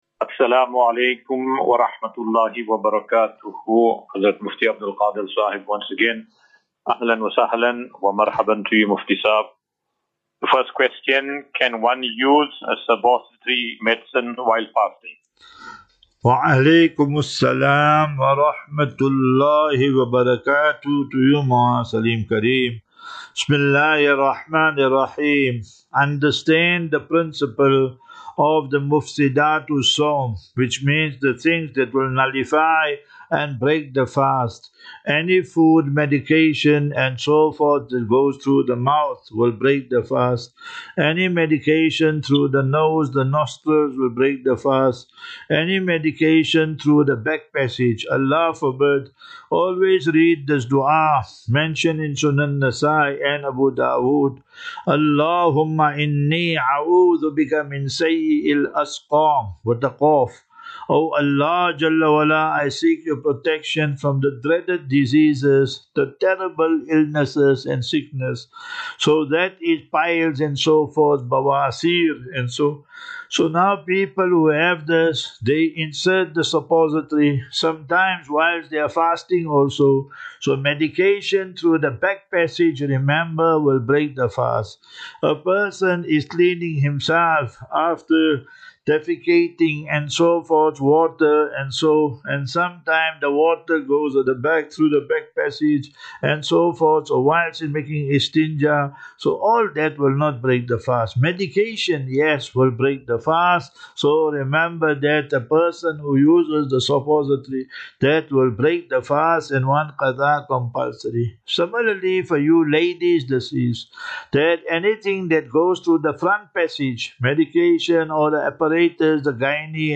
9 Mar 09 March 2025. Assafinatu - Illal - Jannah. QnA
Daily Naseeha.